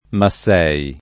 [ ma SS$ i ]